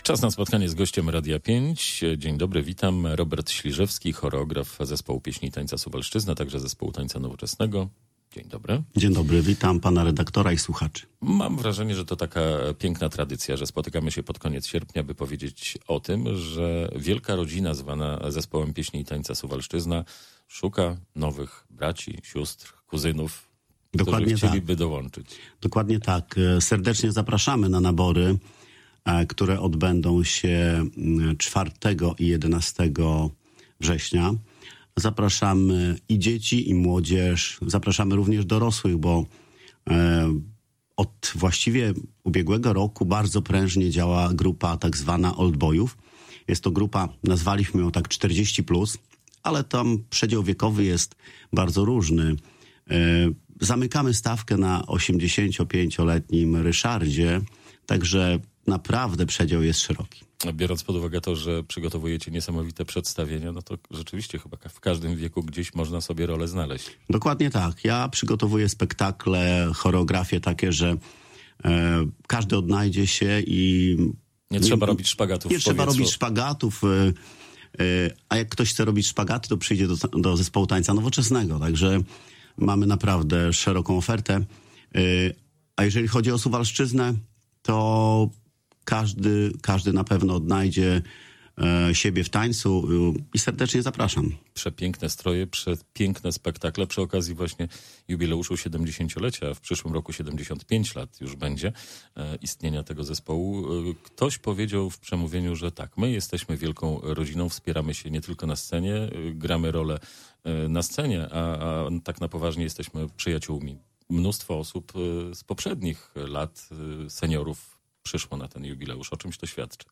Nabór ogłosił Zespół Pieśni i Tańca Suwalszczyzna oraz Grupa Tańca Nowoczesnego w Suwałkach. Zainteresowani powinni zgłaszać się do Suwalskiego Ośrodka Kultury 4 i 5 września. Do udziału zachęcał dziś w Radiu 5